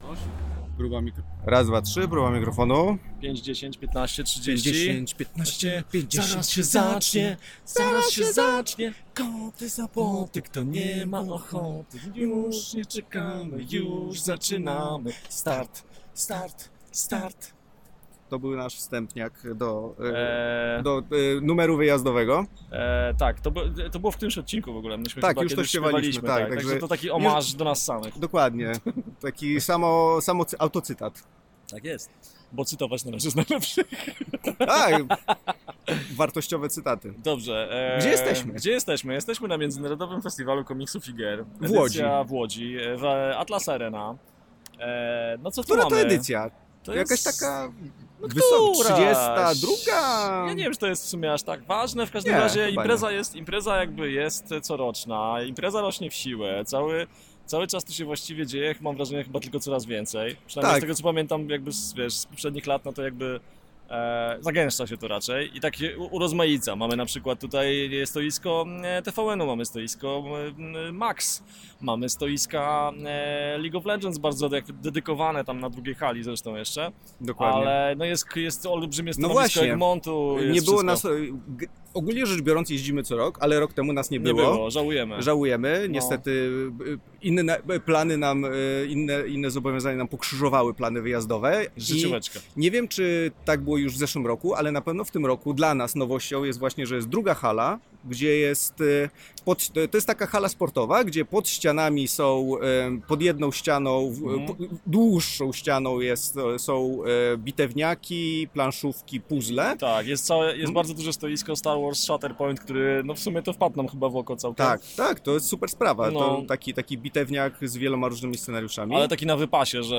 Nasza pierwsza relacja „z terenu”! Jak co roku, pojechaliśmy do Łodzi na Międzynarodowy Festiwal Komiksu i Gier.
Samo audio też jest do przesłuchania, bo po prostu w naszym stylu usiedliśmy sobie w ustronnym miejscu i gadaliśmy przez 10 minut do mikrofonu.